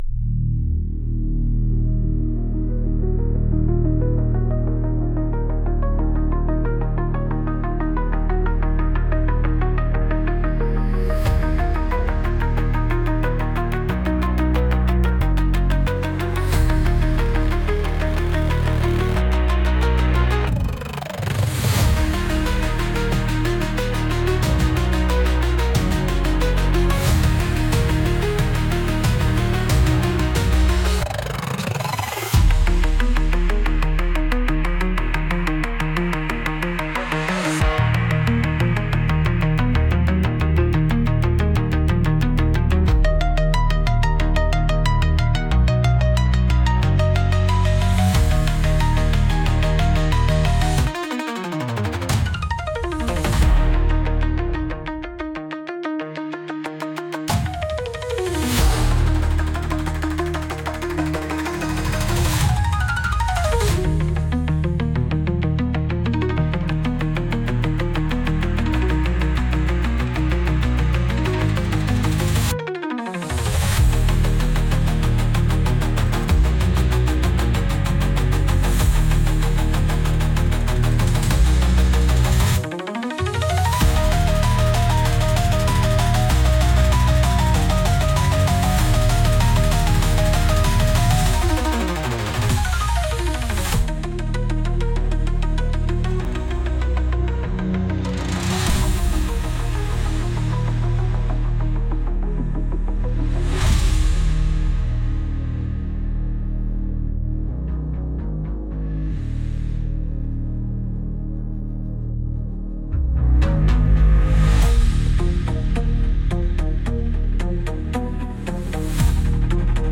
Instrumental - Join Real Liberty Media PEERTUBE Video platform 5.48 .mp3